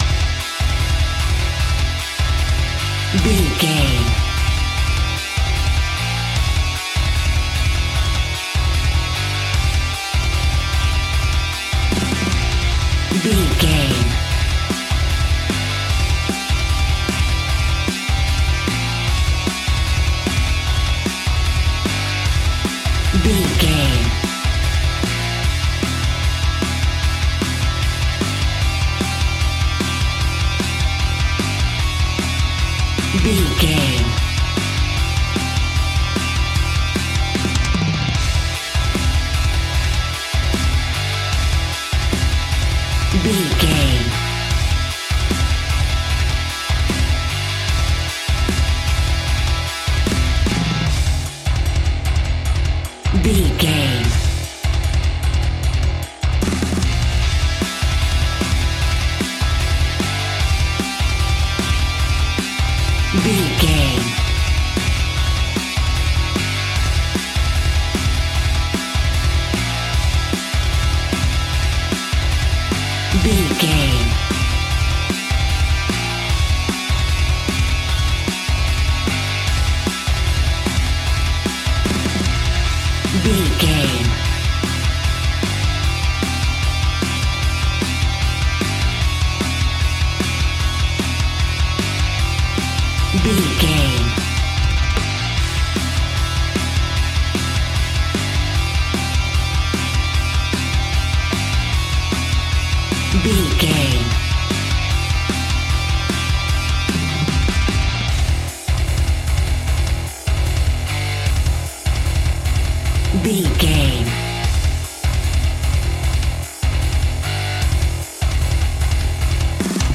Epic / Action
Fast paced
In-crescendo
Aeolian/Minor
bass guitar
electric guitar
drums